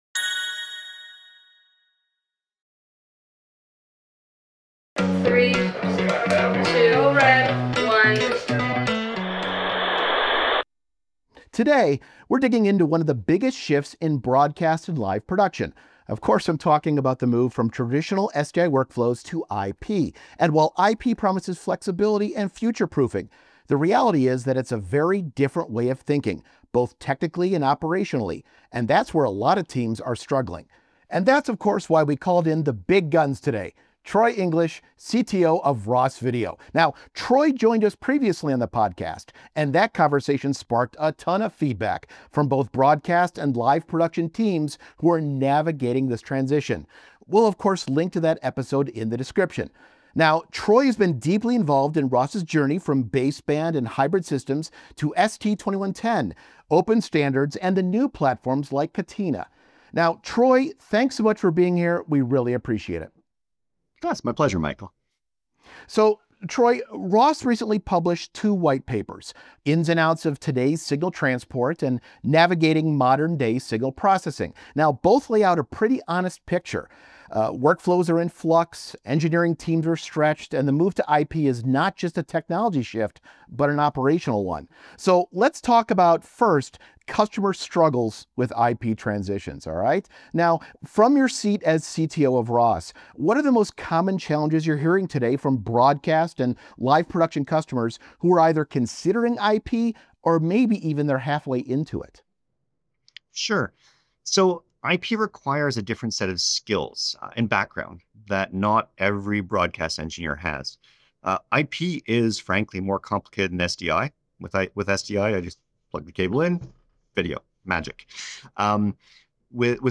Interviews include leaders, experts, and future-thinkers in our industry discussing the challenges with adoption, experiences with new workflows- providing advice that will help you today and into the future.